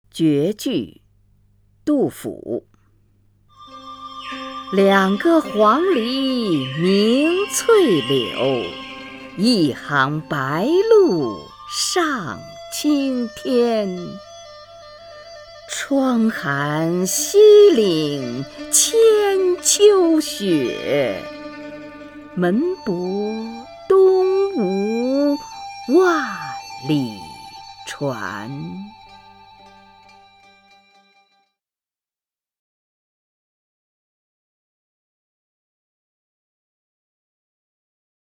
虹云朗诵：《绝句·两个黄鹂鸣翠柳》(（唐）杜甫) （唐）杜甫 名家朗诵欣赏虹云 语文PLUS